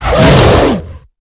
hit3.ogg